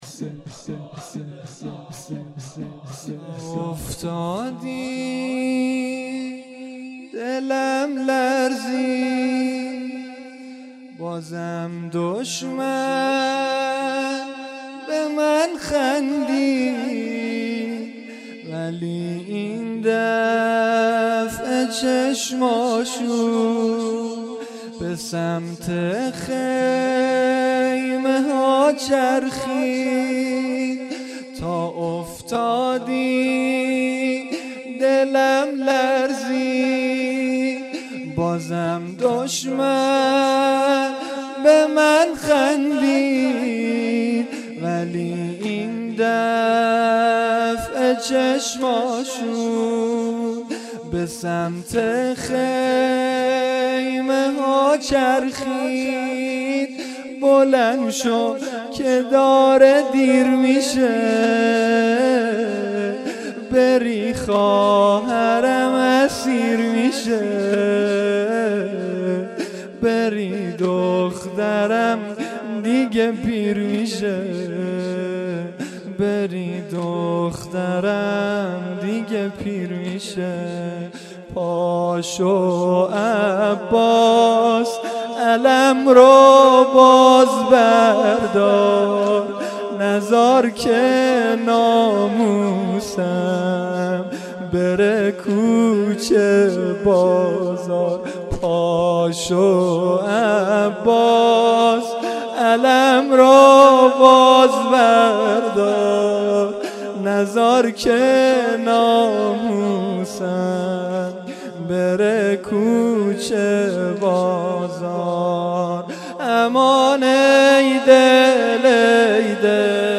(واحد) - شب نهم محرم 98